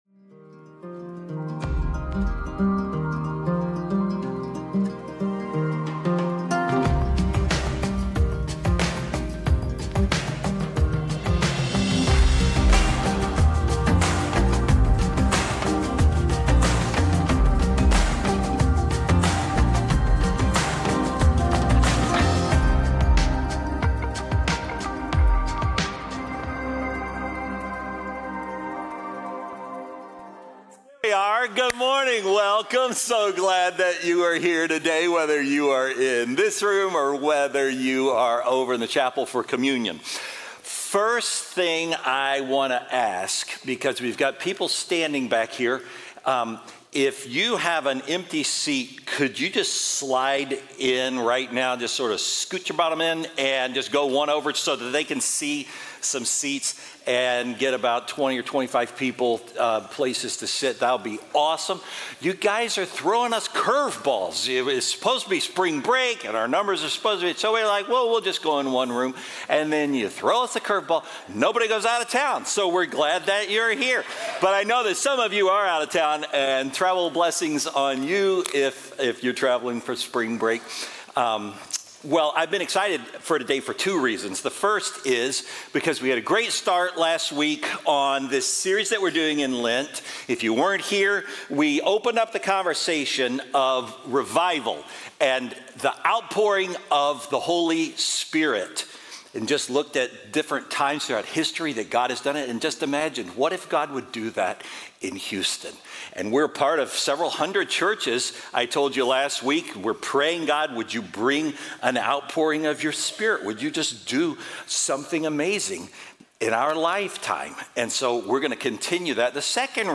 Faithbridge Sermons